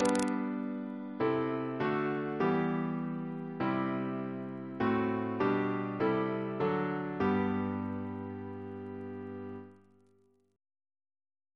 Single chant in G Composer: Rt Rev Thomas Turton (1780-1864), Dean of Peterborough and Westminster, Bishop of Ely Reference psalters: H1940: 623; OCB: 109